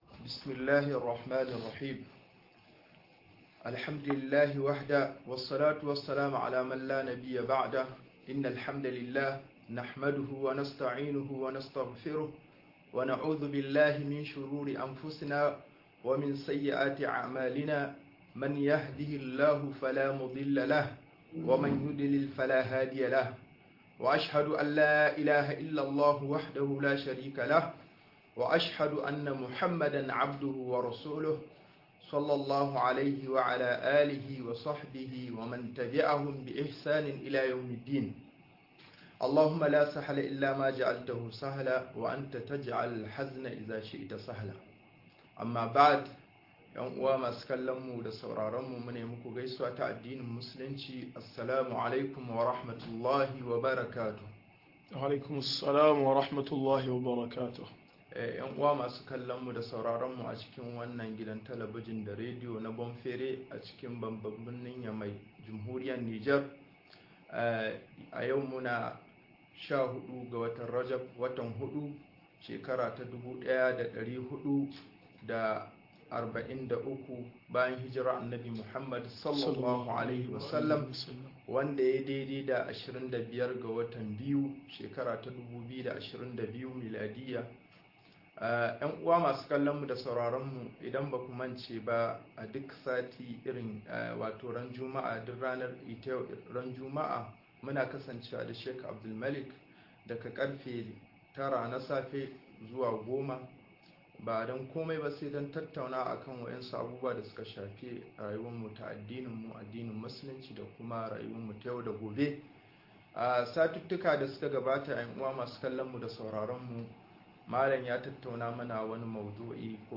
Yin tasiri da tasirantuwa-08 - MUHADARA